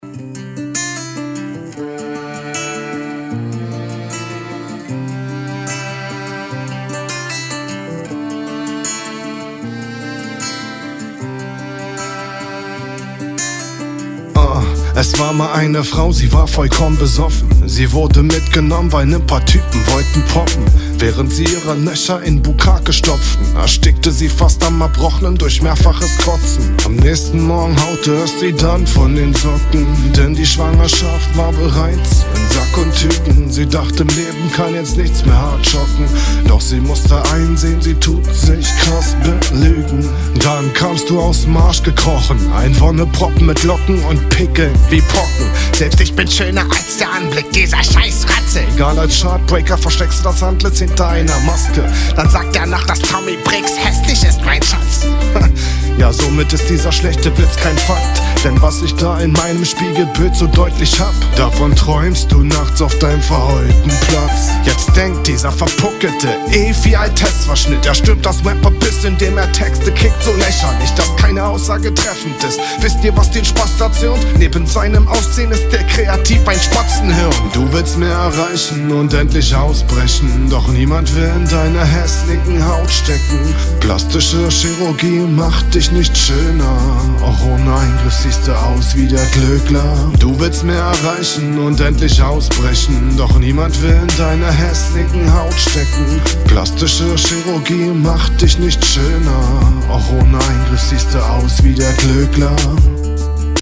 Durch das Konzept und durch den Beat kommt irgendwie keine richtige Battleatmosphäre auf.